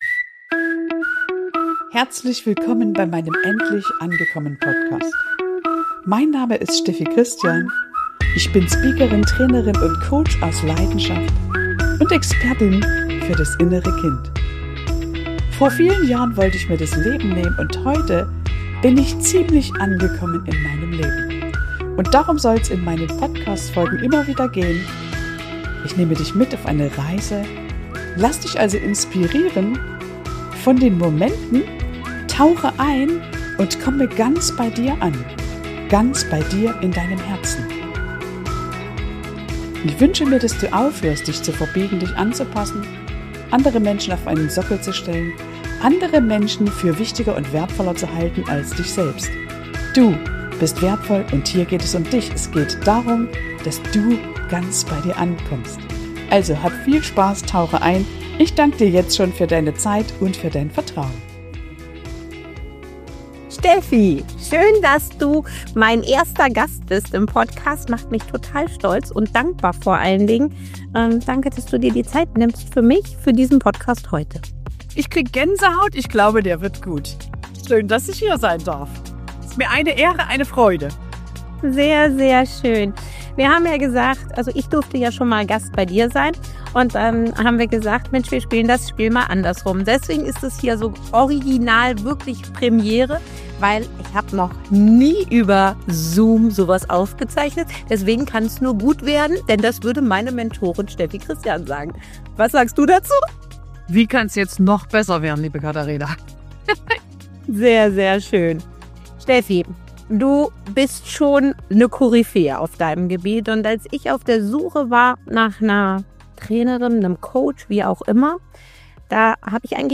Ein tiefes, ehrliches Gespräch über innere Führung, göttliche Klarheit – und die Kraft, sich selbst wieder zu vertrauen.